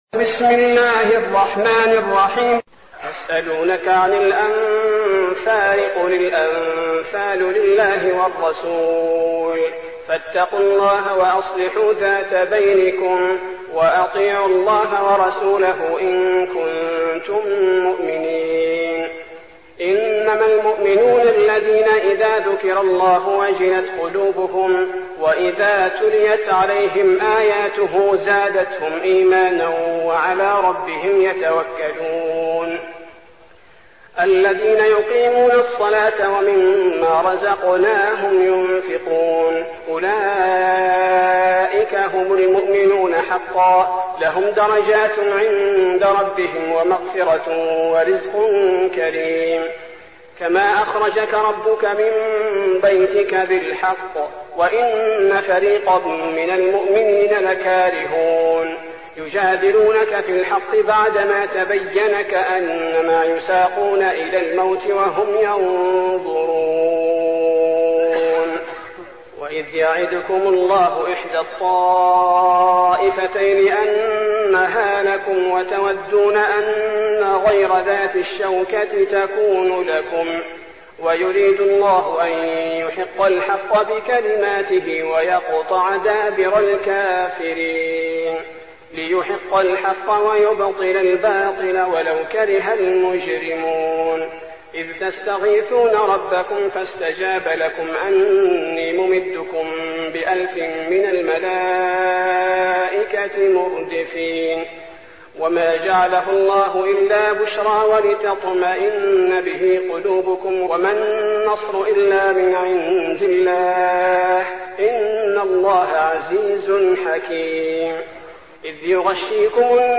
المكان: المسجد النبوي الشيخ: فضيلة الشيخ عبدالباري الثبيتي فضيلة الشيخ عبدالباري الثبيتي الأنفال